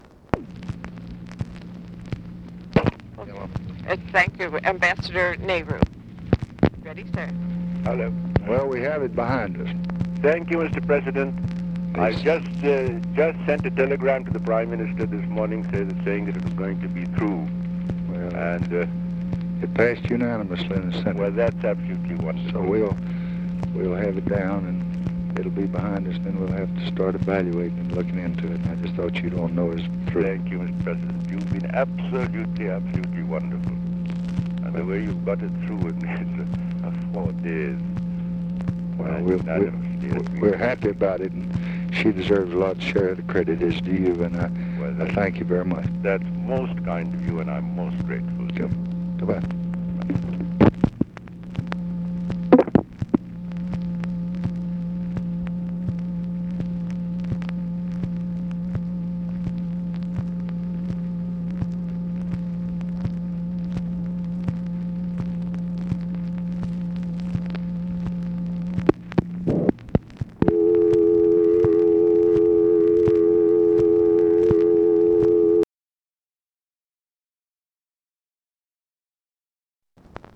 Conversation with B. K. NEHRU, April 6, 1966
Secret White House Tapes